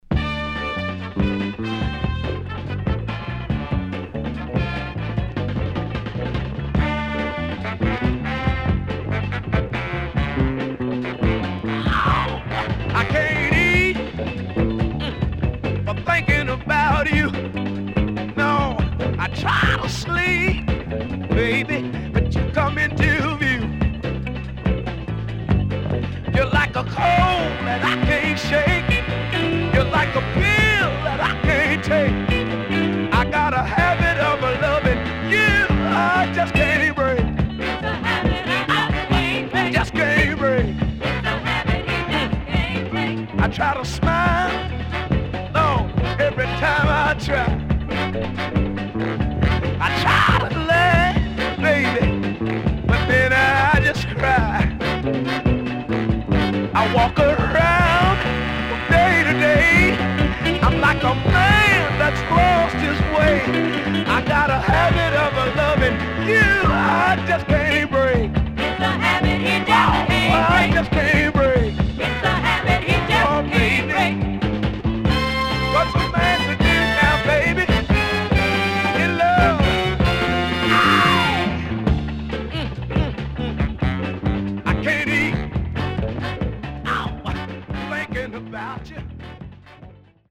HOME > SOUL / OTHERS
71年Great Vocal.3216